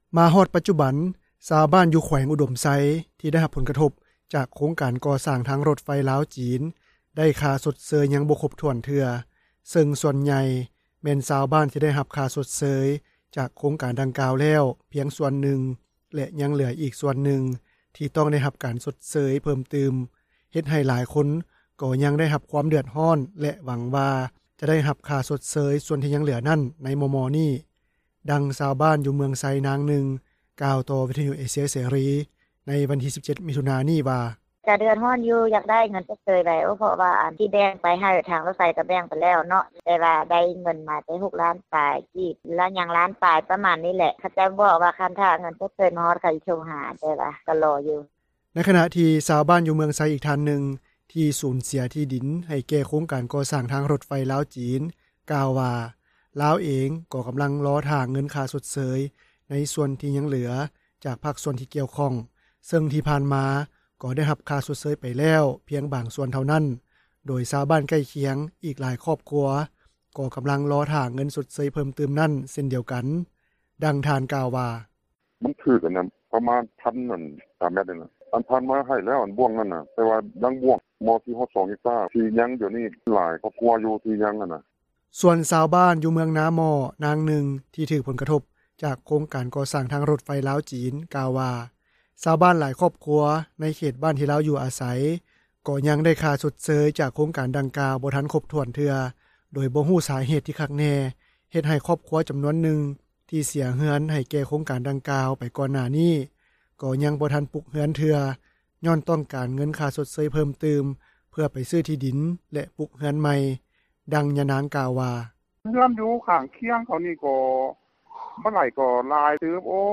ດັ່ງຊາວບ້ານ ຢູ່ເມືອງໄຊ ນາງນຶ່ງ ກ່າວຕໍ່ວິທຍຸເອເຊັຽເສຣີ ໃນວັນທີ 17 ມິຖຸນາ ນີ້ວ່າ: